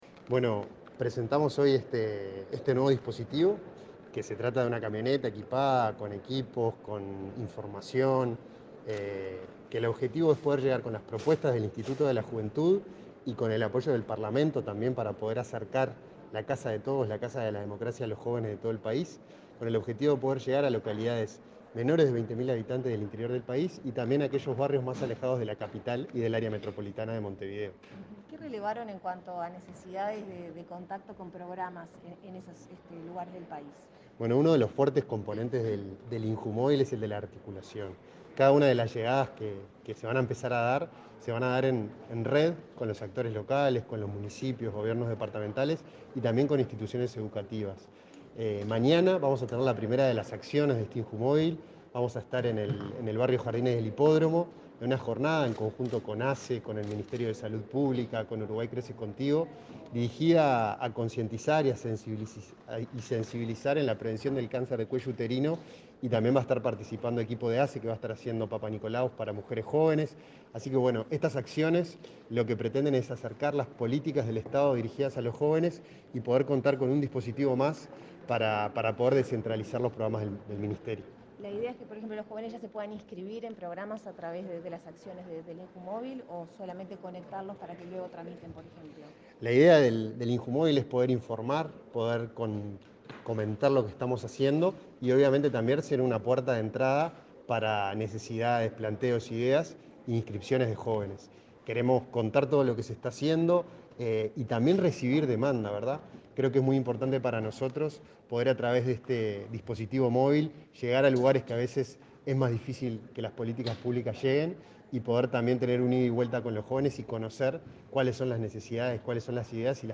Declaraciones a la prensa del director del INJU, Felipe Paullier
El director del Instituto Nacional de la Juventud (INJU), Felipe Paullier, dialogó con la prensa, luego de participar este jueves 24 de la